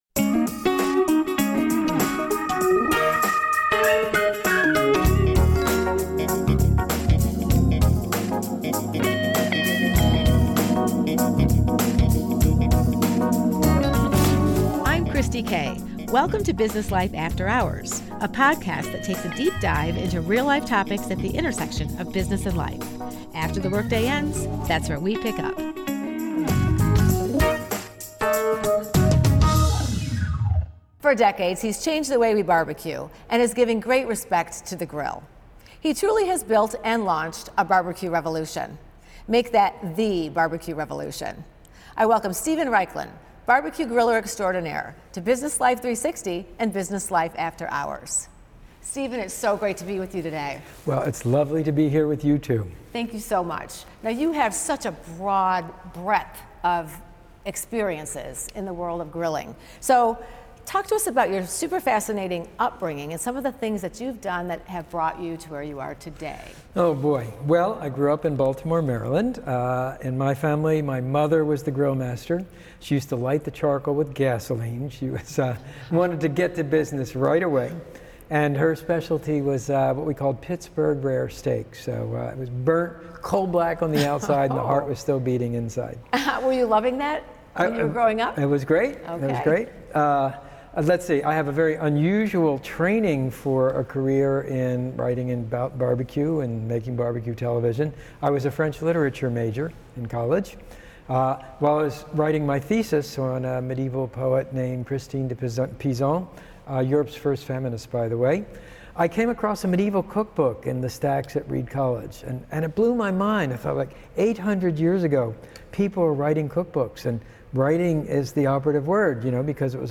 A Conversation Steven Raichlen, PBS BBQ Griller Extraordinaire - WGTE Public Media